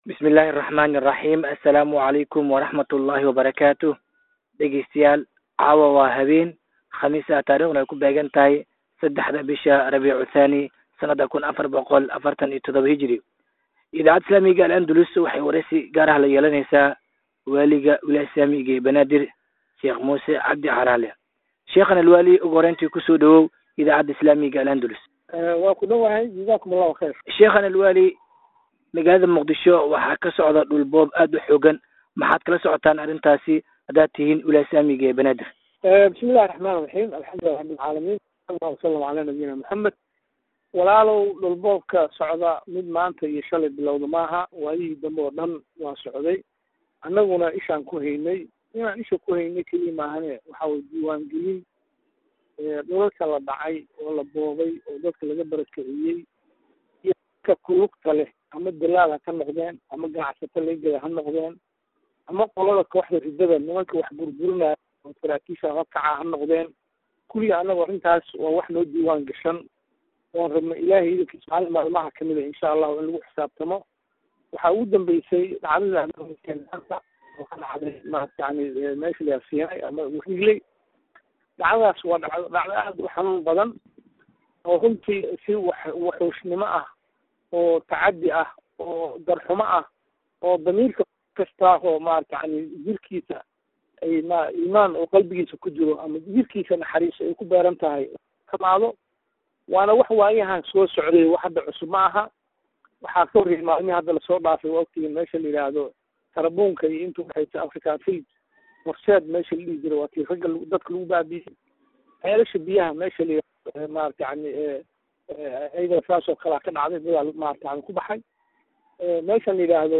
waraysi uu siiyay idaacadda islaamiga ah ee Al-andalus